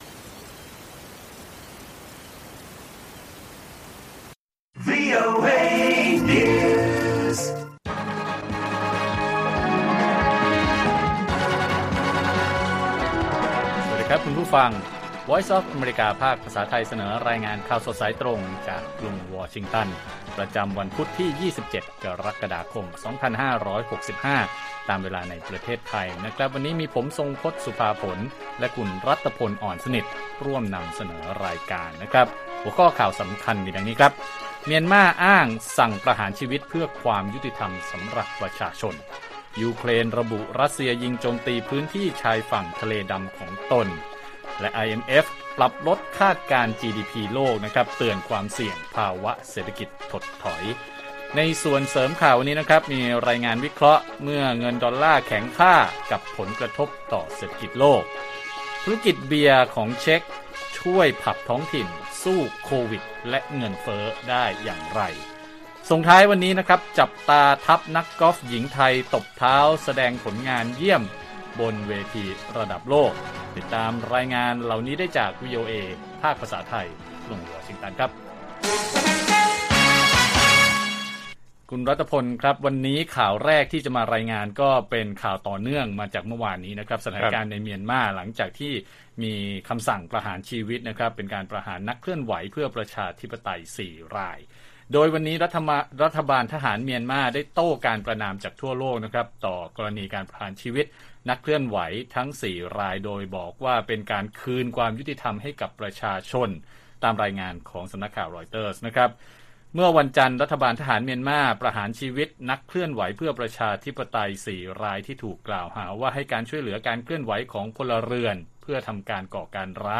ข่าวสดสายตรงจากวีโอเอไทย 8:30–9:00 น. วันที่ 27 ก.ค. 65